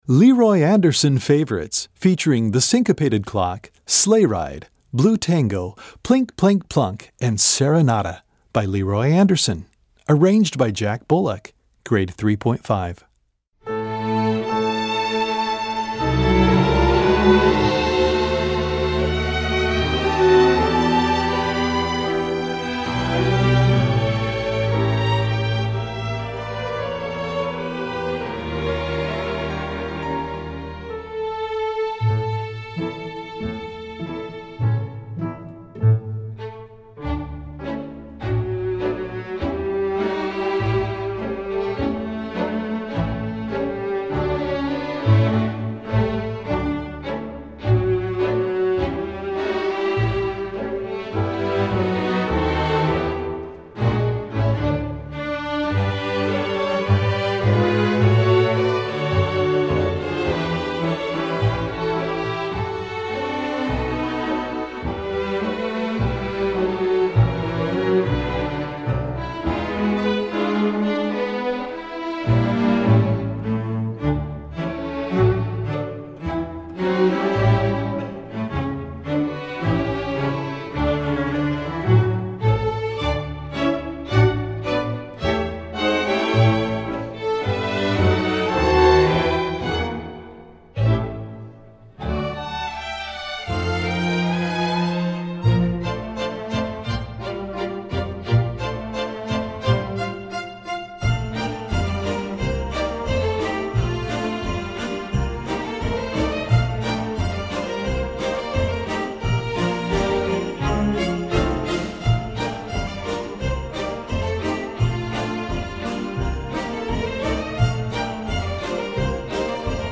Gattung: Sinfonieorchester
Besetzung: Sinfonieorchester